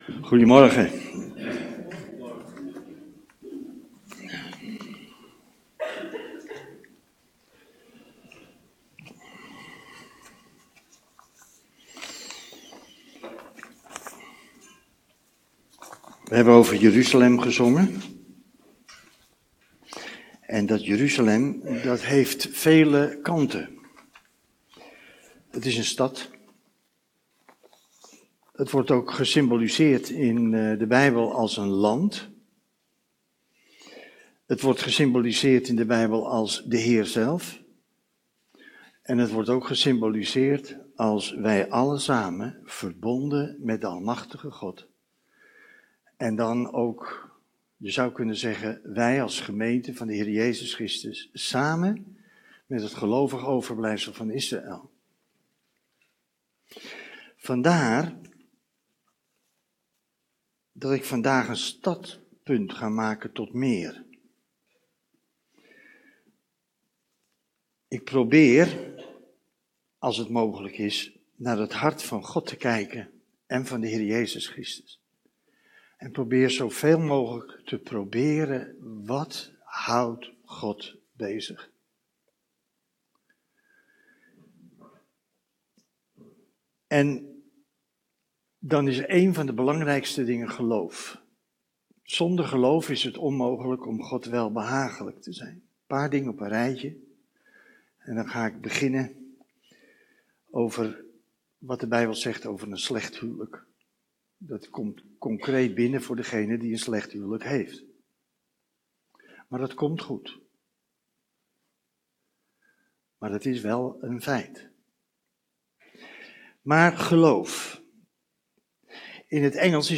Geplaatst in Preken